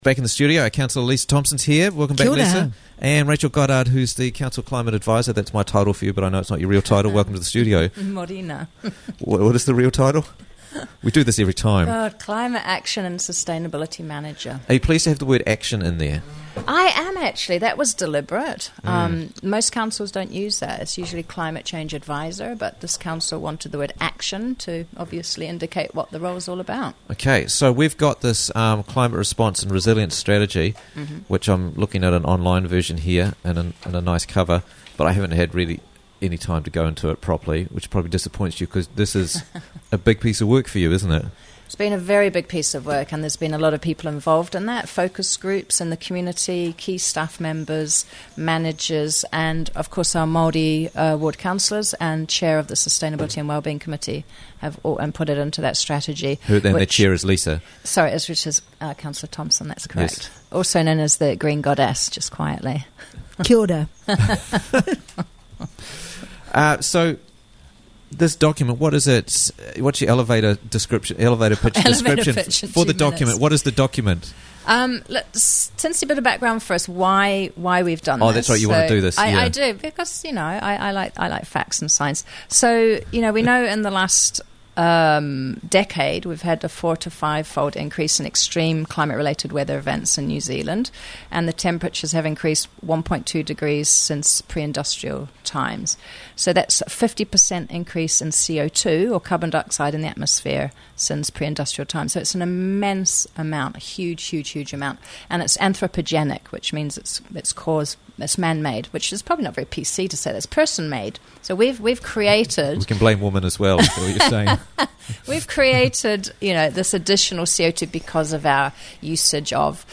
Council has a Climate Strategy - Interviews from the Raglan Morning Show